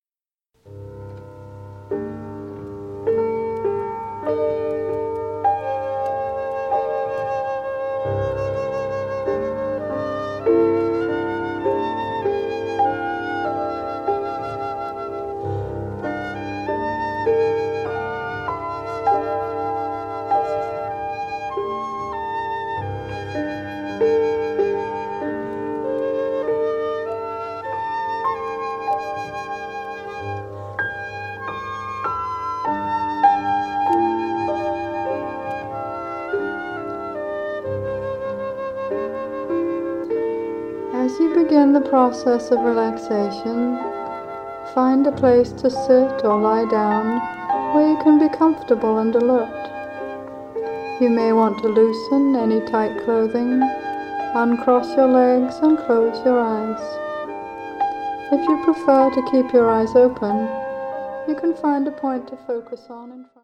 Seasons for Healing: Spring (Guided Meditation)
Piano and Synthesizer
Flute